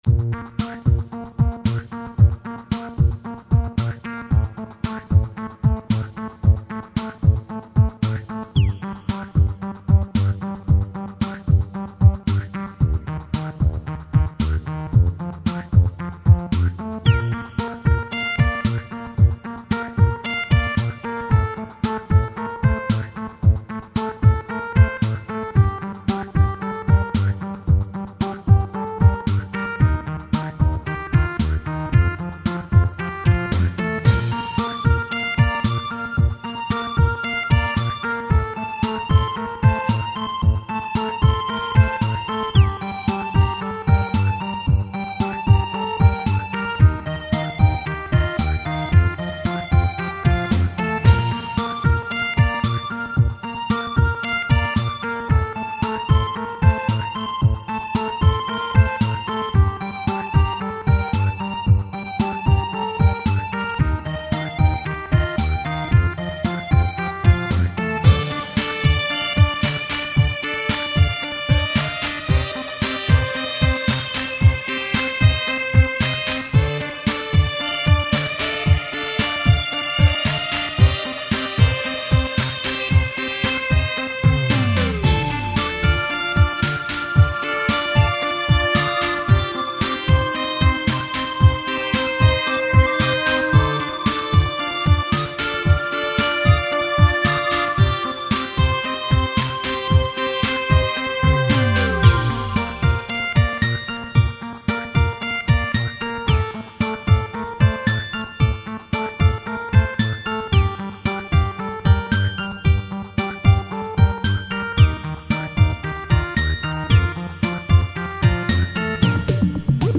File under: Cheap Electronica